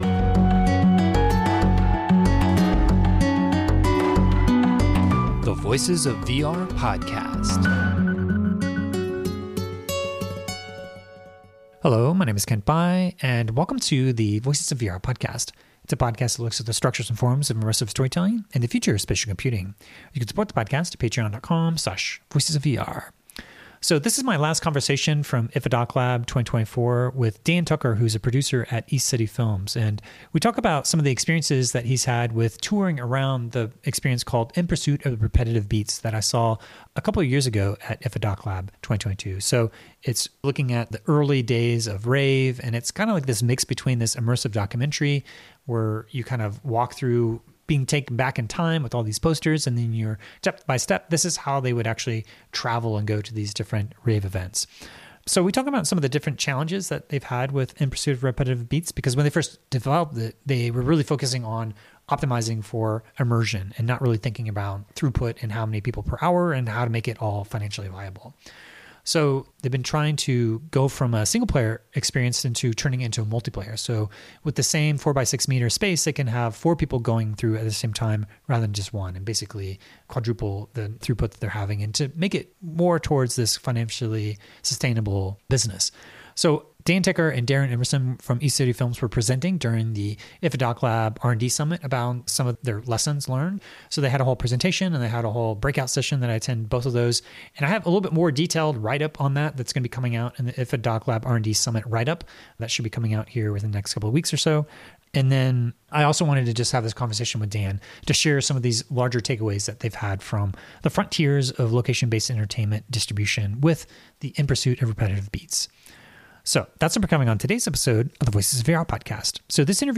Oral history podcast featuring the pioneering artists, storytellers, and technologists driving the resurgence of virtual & augmented reality. Learn about the patterns of immersive storytelling, experiential design, ethical frameworks, & the ultimate potential of XR.